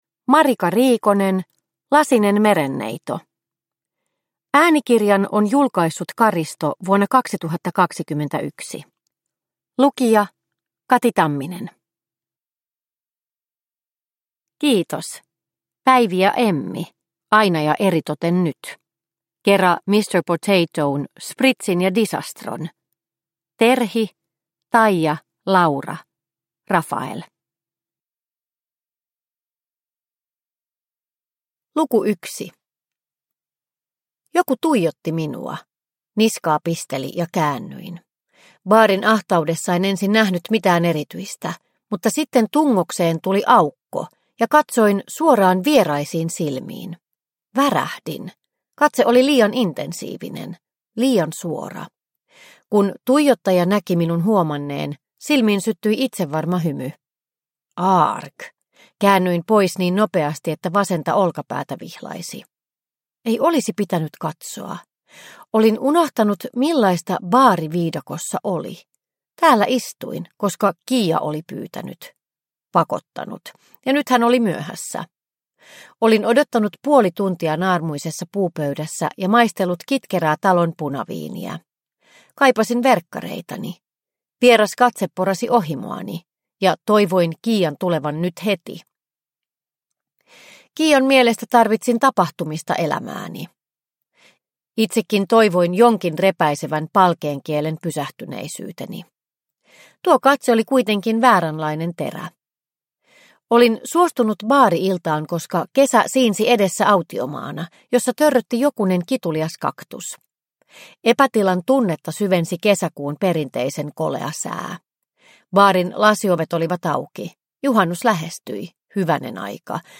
Lasinen merenneito – Ljudbok – Laddas ner